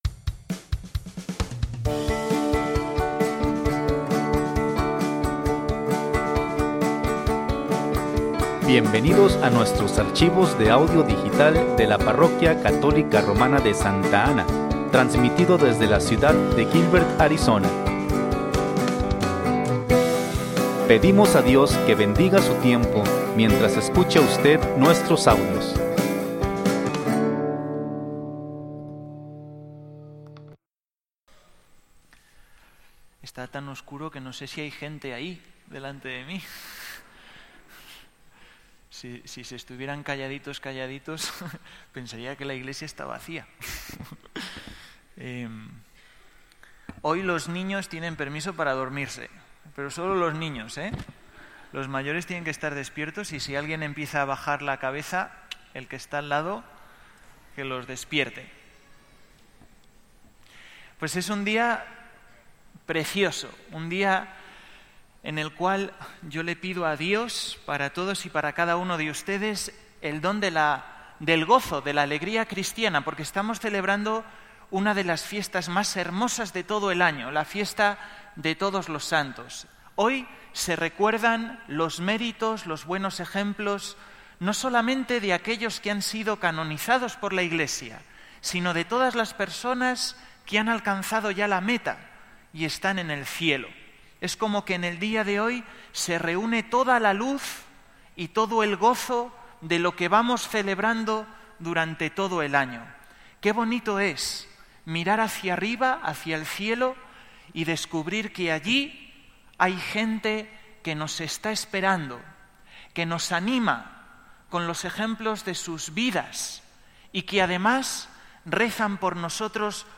Homilias